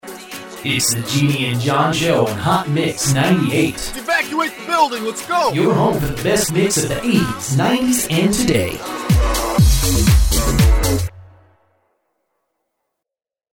A professional voice actor with a warm, trustworthy, and versatile sound.
DJ Drop